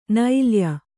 ♪ nailya